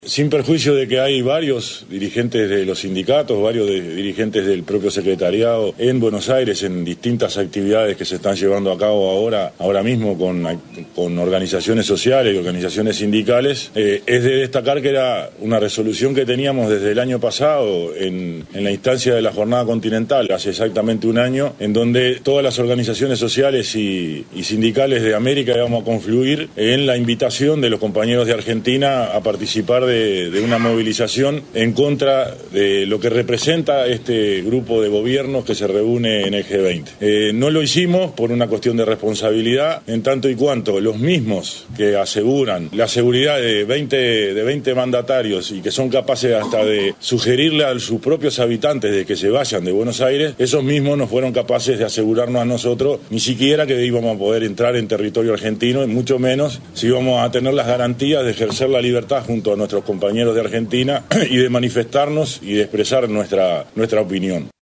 en conferencia de prensa